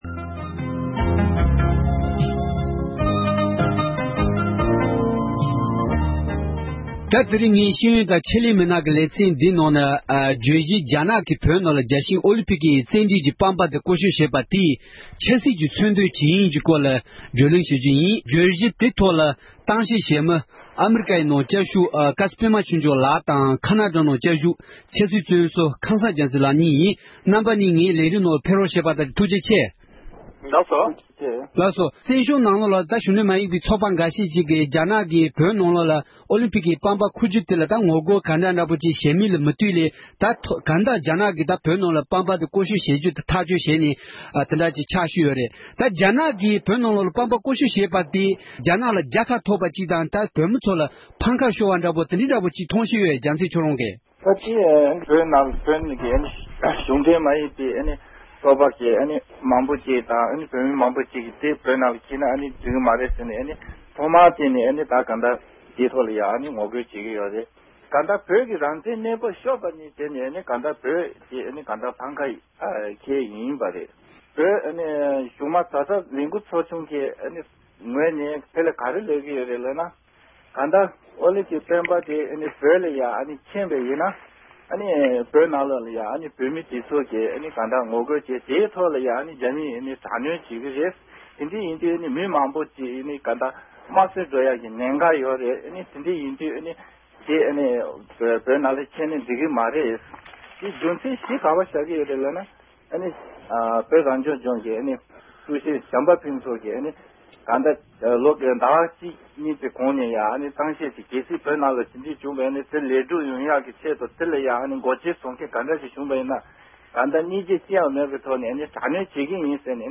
བཅའ་འདྲི་ཞུས་པ་ཞིག་གསན་རོགས་གནང༌༎